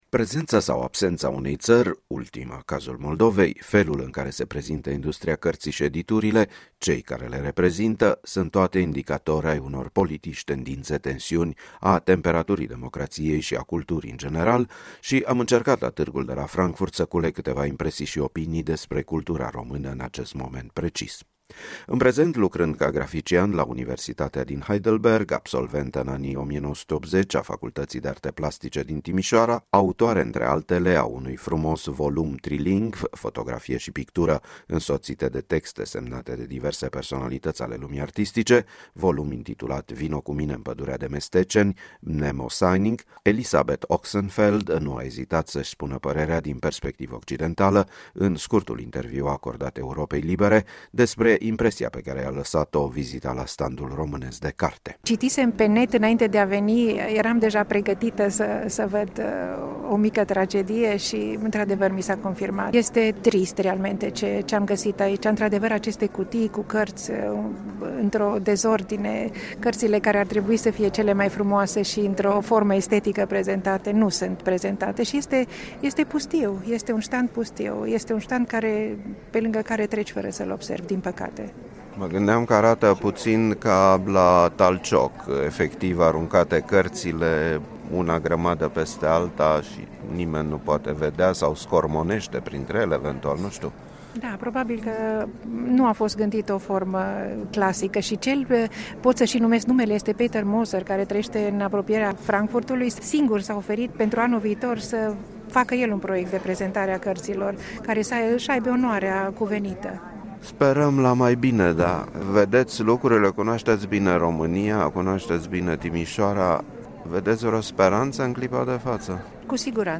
Opinii culese la Tîrgul Internațional de Carte de la Frankfurt pe Main